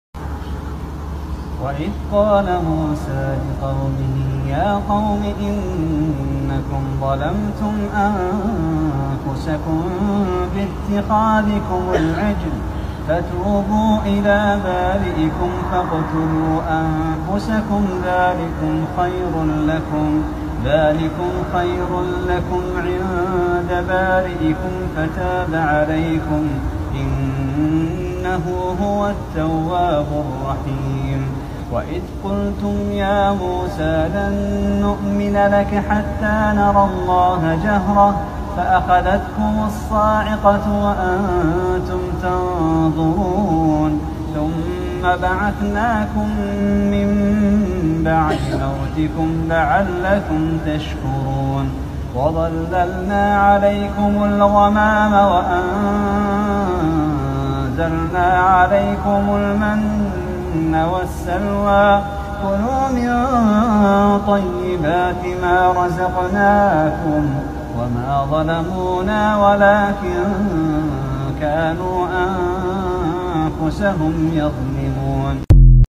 ماتيسر من سورة(البقرة) بصوت الشيخ